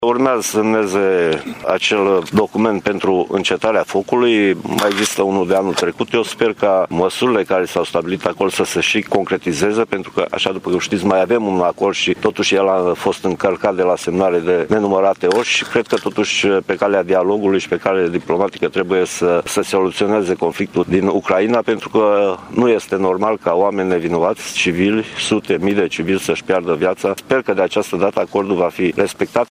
Asa a declarat astăzi, la Tîrgu-Mureș, ministrul Apărării Nationale, Mircea Dușa.
Ministrul Apărării Naționale, Mircea Dușa: